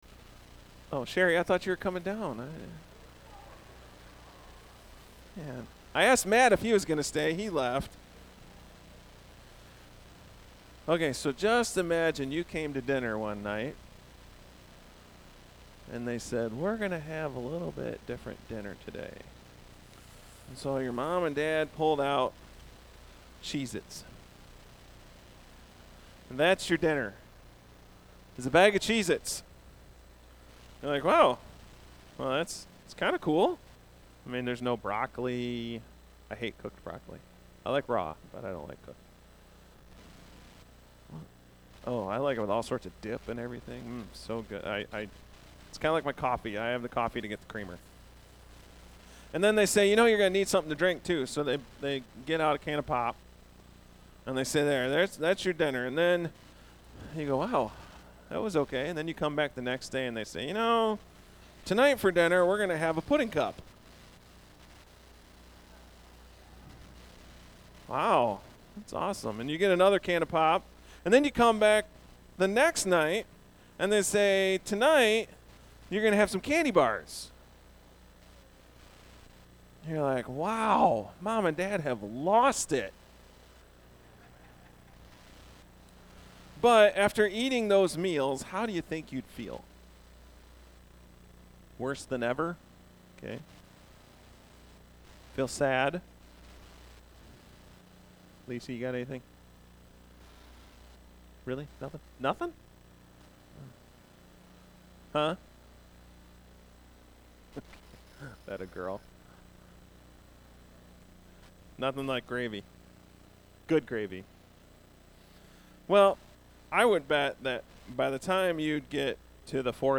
A message from the series "Apologetics."